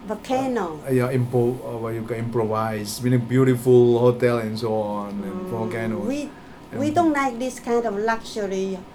S1 = Taiwanese female S2 = Indonesian male Context: They are talking about places to stay in Bali.
Intended Words: improvise meaning Heard as: provide many Discussion: The use of improvise is perhaps an unusual lexical choice here; but there seems nothing unexpected about the pronunciation.